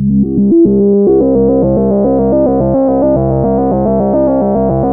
JUP 8 C3 11.wav